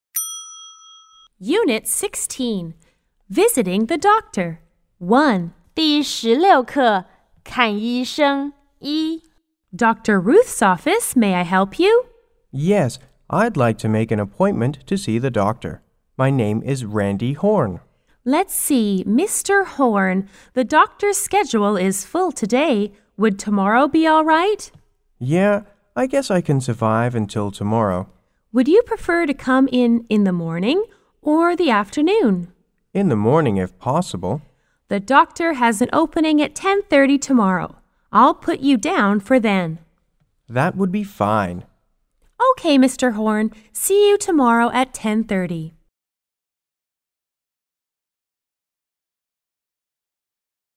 R= Receptionist P= Patient